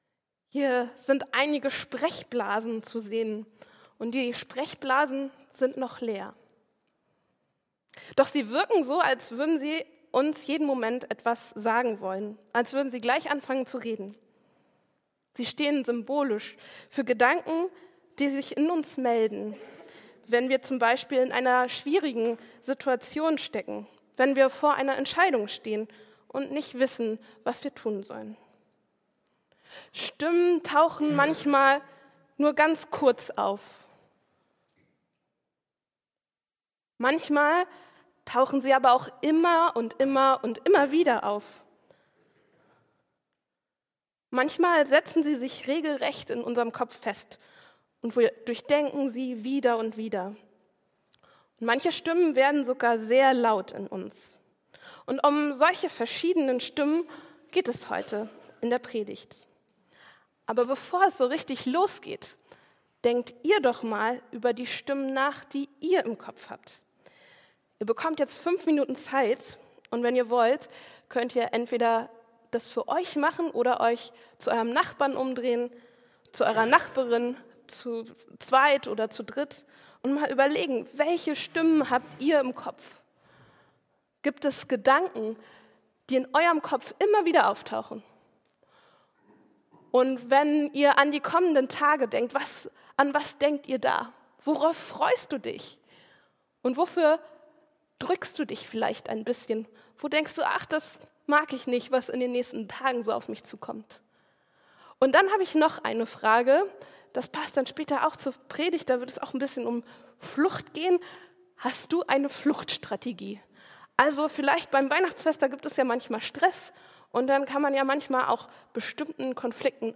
Josef erscheint gleich zweimal ein Engel im Traum. Die Texte zur Predigt stehen in Matthäus 1, 18-25 und Matthäus 2, 13-15.